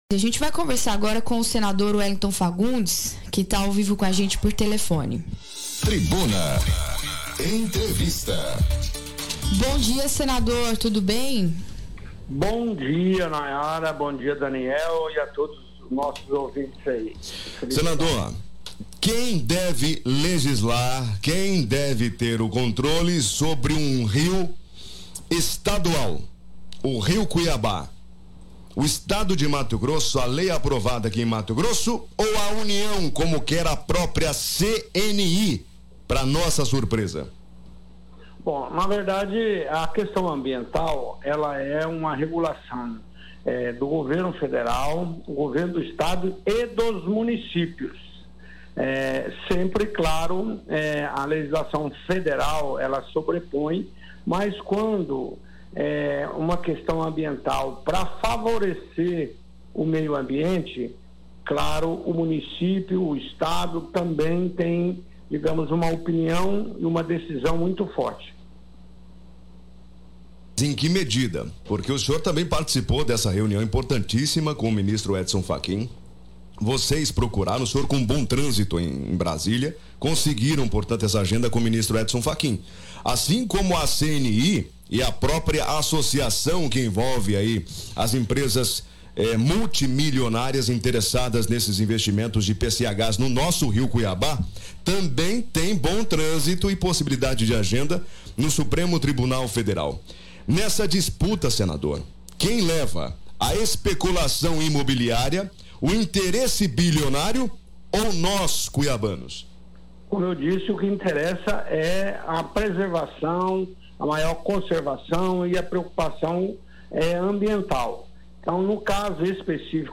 Entrevista realizada por telefone
Fagundes foi o entrevistado desta quarta-feira (05.04) no programa Tribuna, da Rádio Vila Real FM.
Entrevista_Vila_Real.mp3